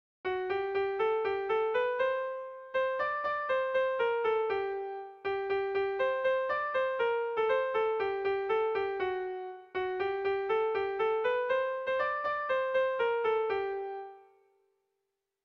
Irrizkoa
ABDEAB